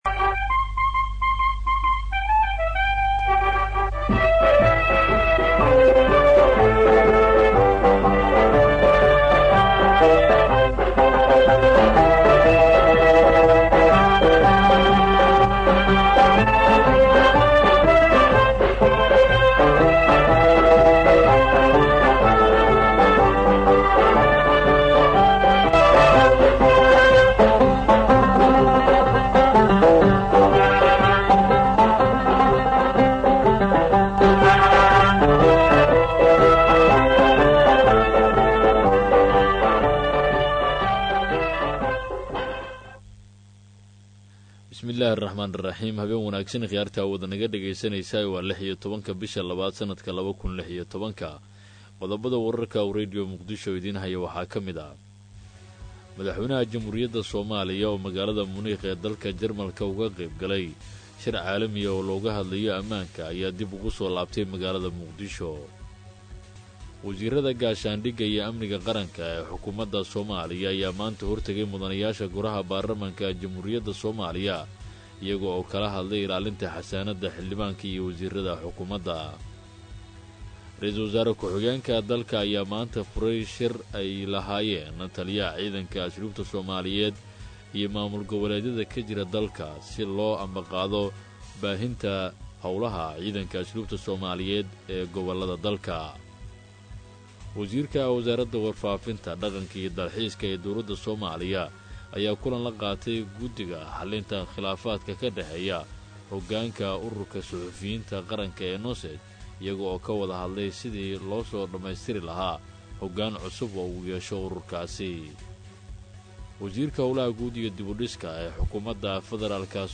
Dhageyso Warka Habeen ee Radio Muqdisho